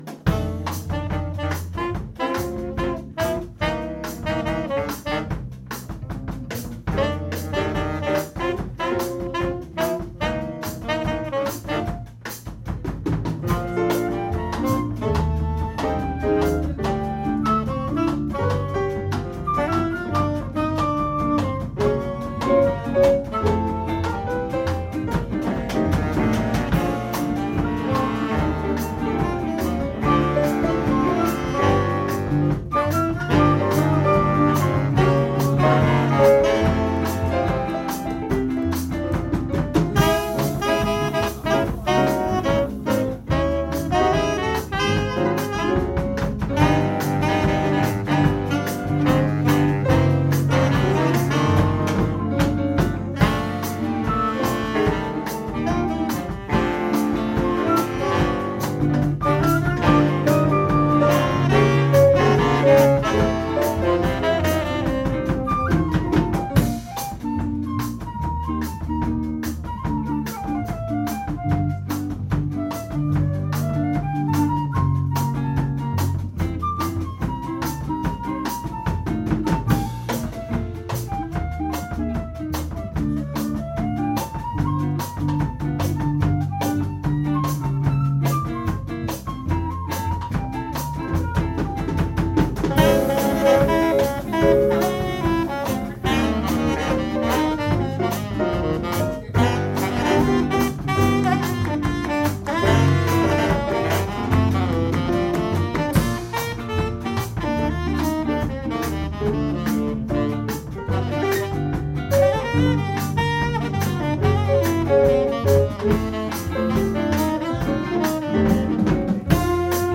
Jazz-nonetten på Lautrupgaard - optagelser 2025
Lyd i mp3-format, ca. 192 kbps, optaget stereo - ikke studieoptagelser!.
Tom_Collins_Bossa.mp3